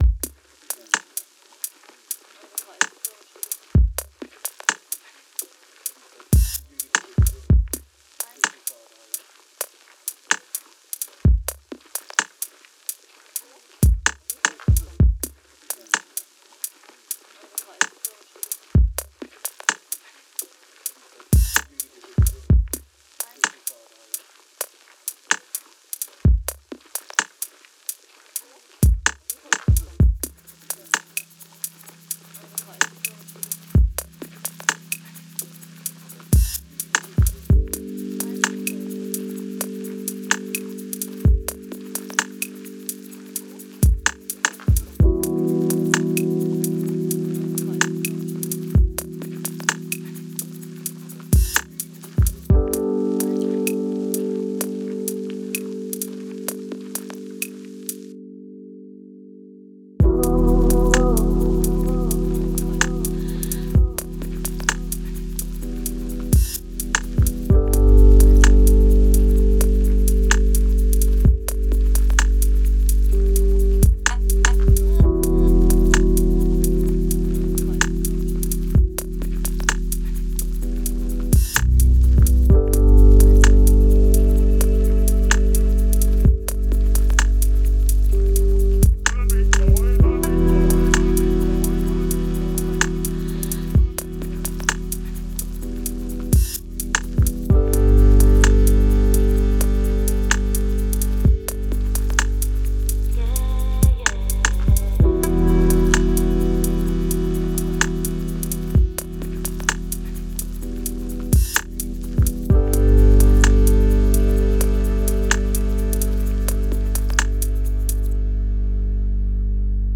Genre: Downtempo, Dub.